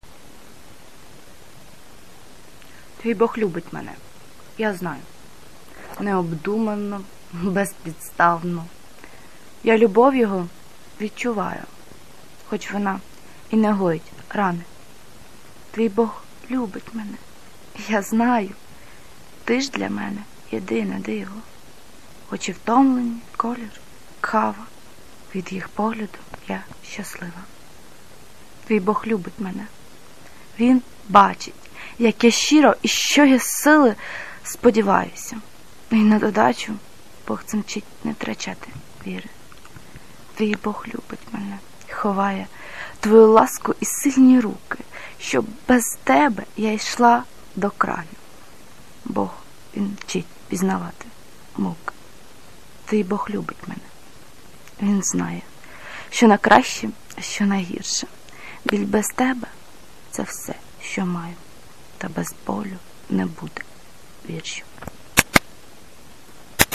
гарний голос give_rose А вірш взагалі - бомбезний!!